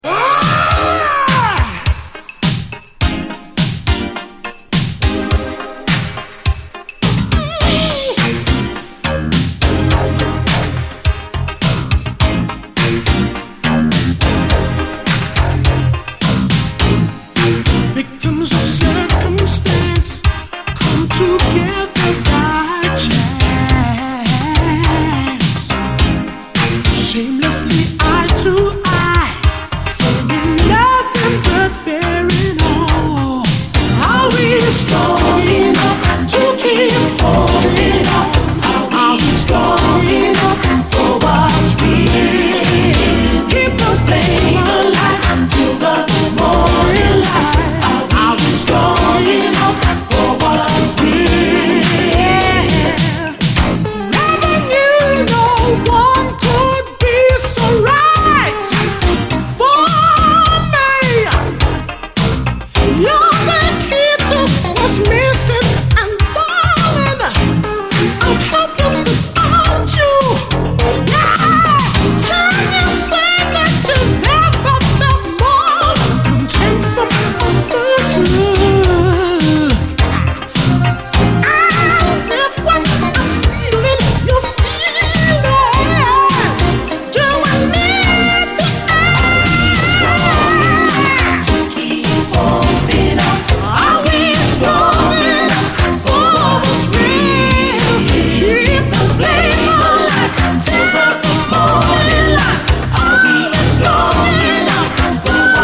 試聴ファイルはこの盤からの録音です
恐ろしく洗練されたエレクトロ・メロウ・シンセ・ファンク
このアルバムはフランス録音です。